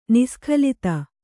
♪ niskhalita